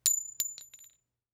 CassingDrop 02.wav